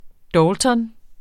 Udtale [ ˈdʌltʌn ]